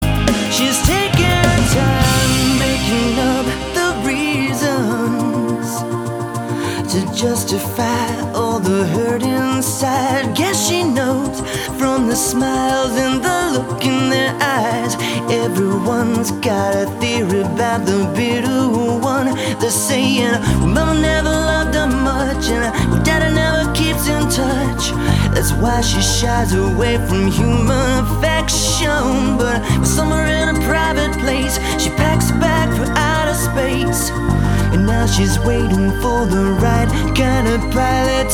• Качество: 320, Stereo
поп
мужской вокал
Pop Rock
романтичные